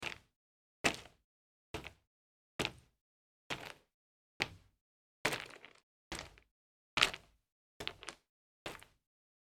steps.mp3